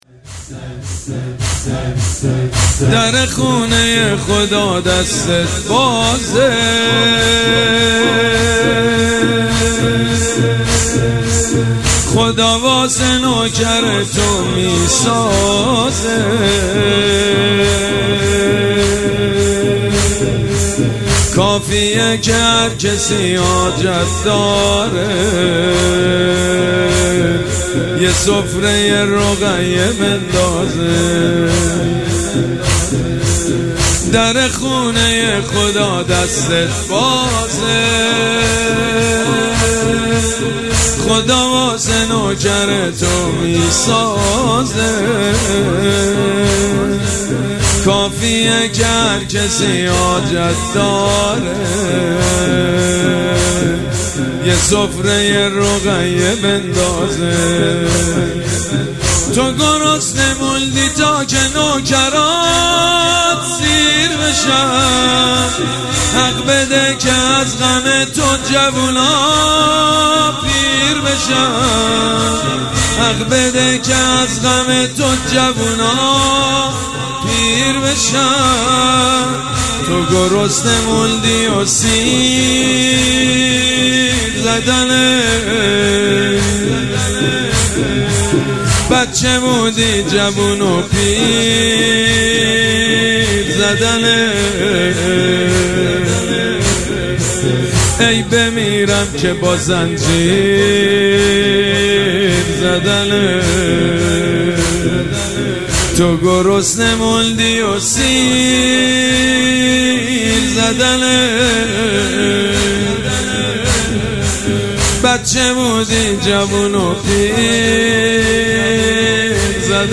شب سوم مراسم عزاداری اربعین حسینی ۱۴۴۷
مداح
حاج سید مجید بنی فاطمه